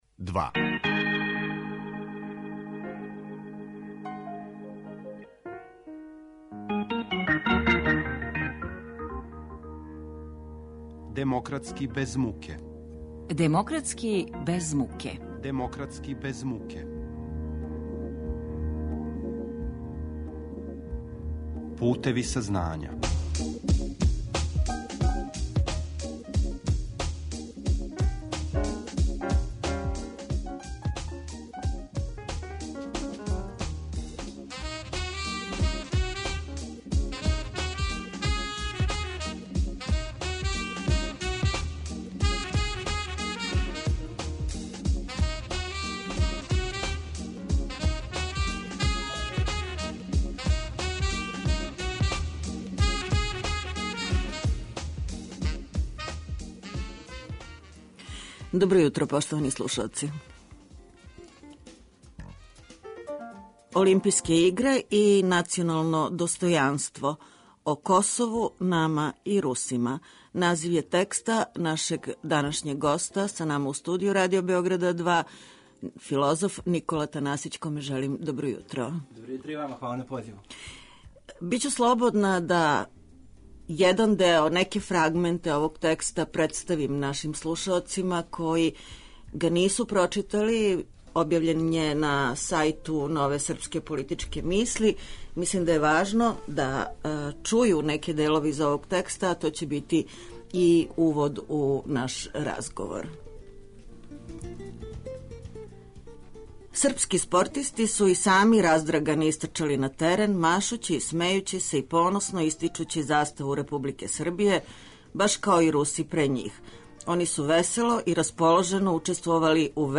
Говори филозоф